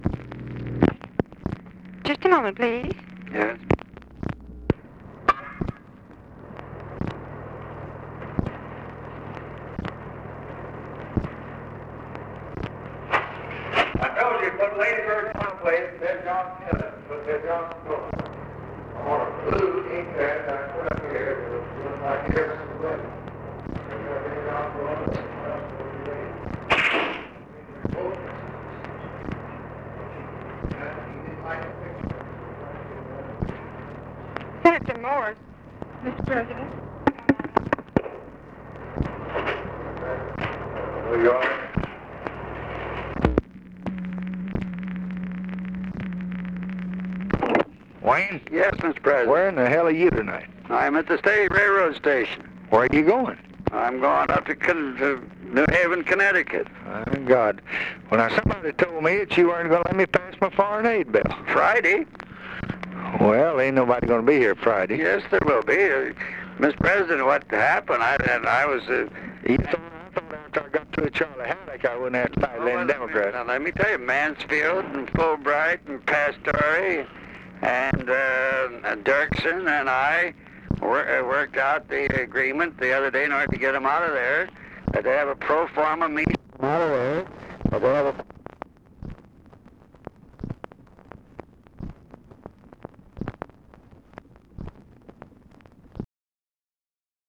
Conversation with WAYNE MORSE, December 24, 1963
Secret White House Tapes